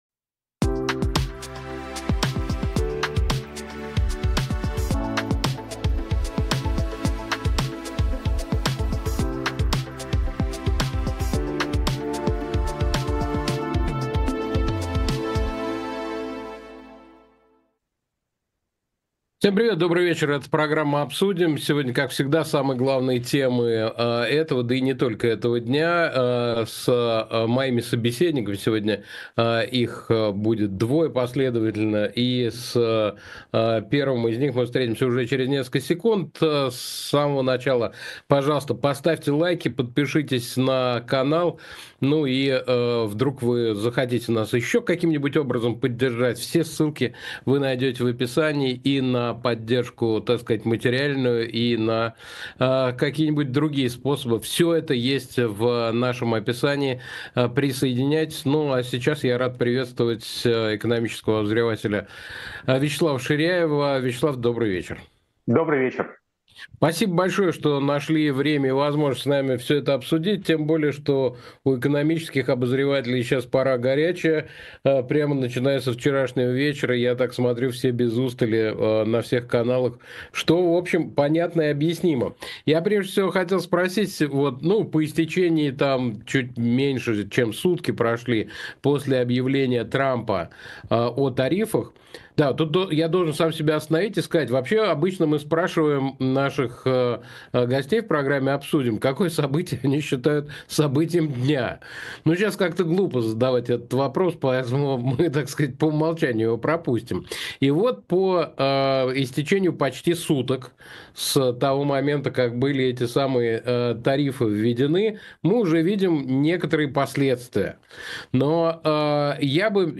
Ведёт эфир Александр Плющев